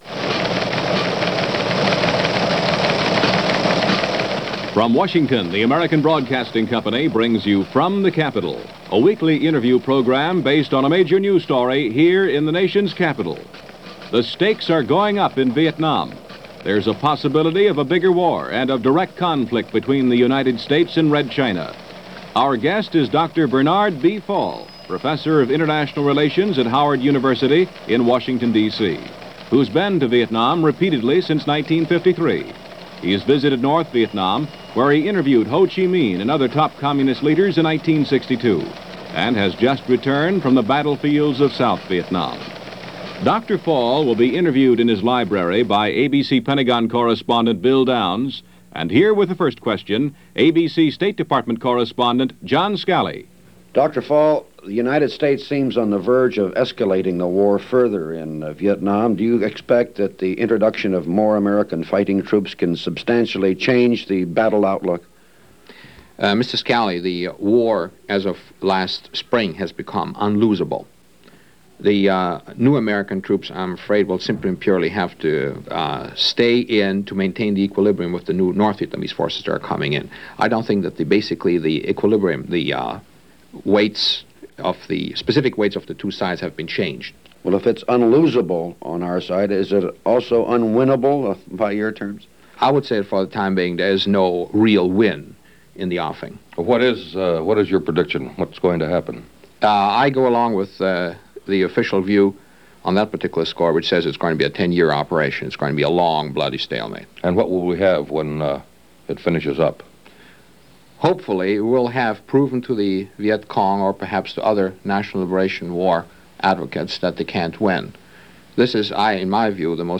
War correspondent Dr. Bernard Fall is interviewed - From The Capitol.